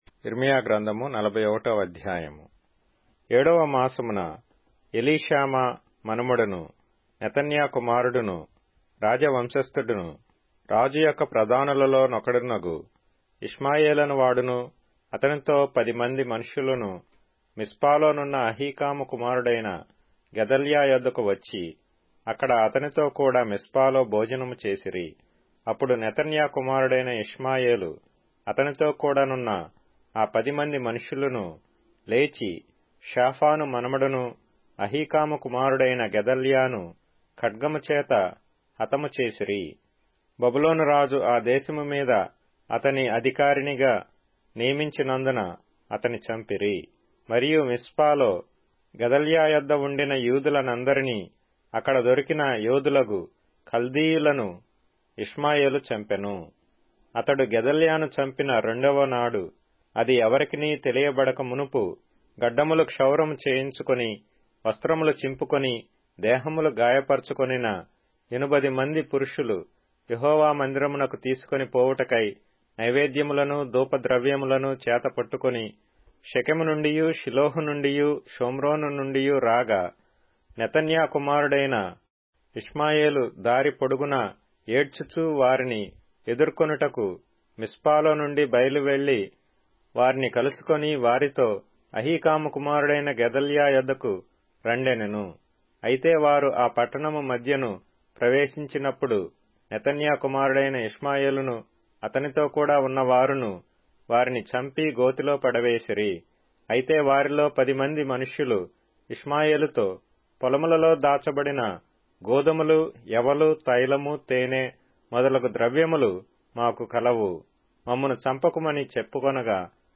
Telugu Audio Bible - Jeremiah 43 in Irvmr bible version